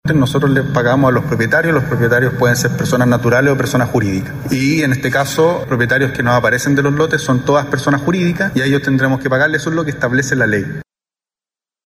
Una vez determinado el valor de los terrenos, el Estado pagará a los propietarios de los predios, que en este caso corresponden sociedades inmobiliarias y personas jurídicas, según explicó el ministro Gajardo.